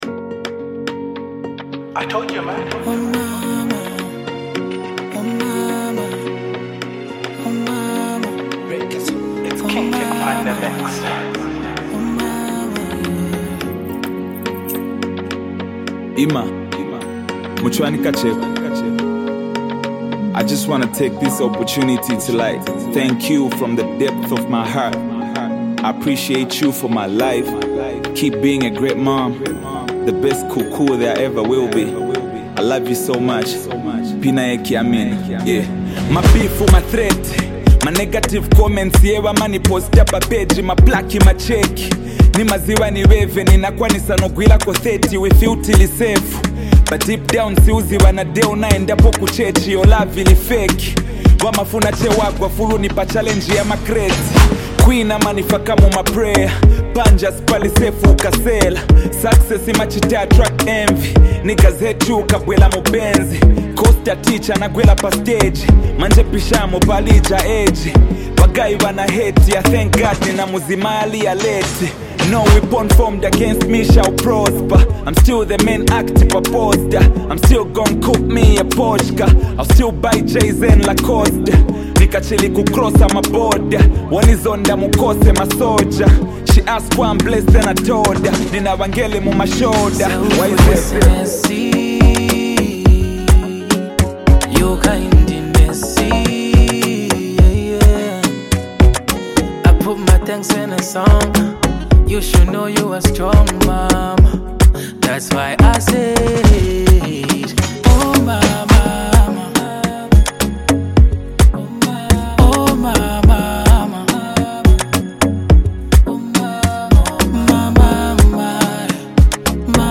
soulful voice